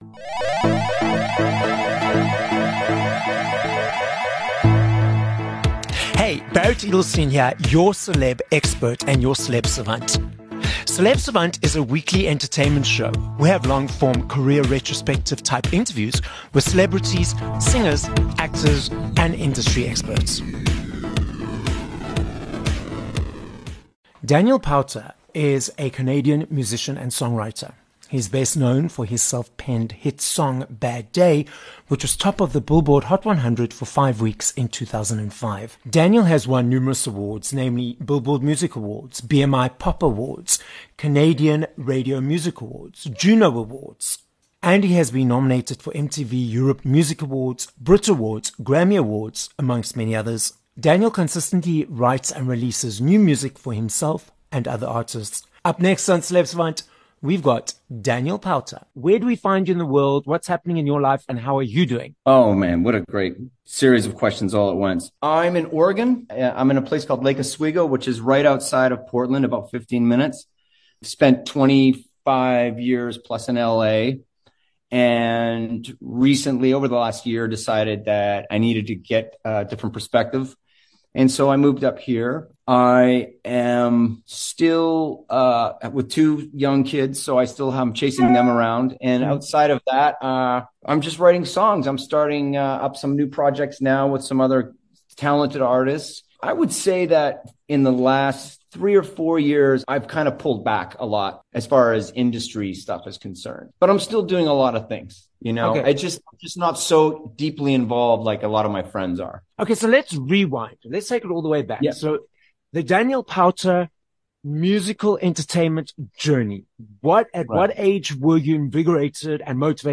12 Jun Interview with Daniel Powter